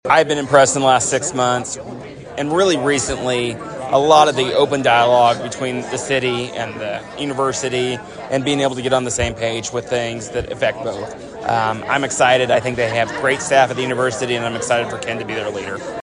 City Manager Trey Cocking:
2020-cocking-excited.mp3